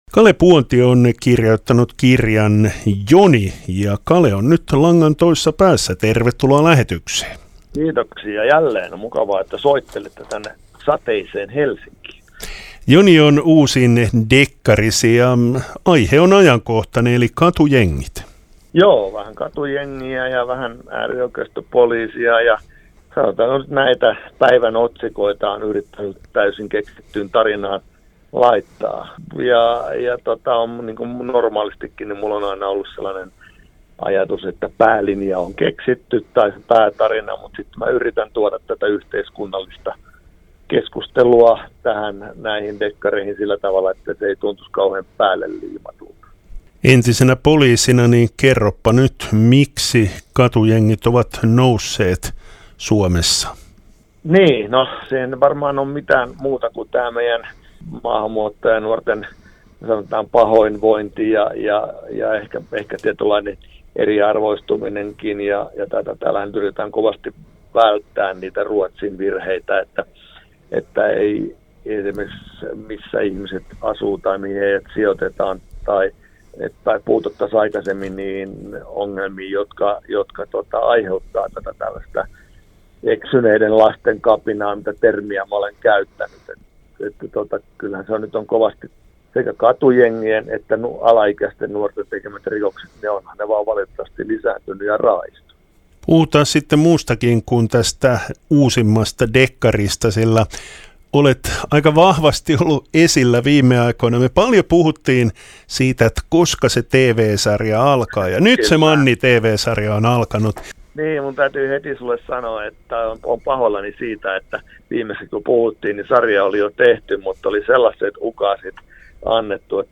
haastattelussa.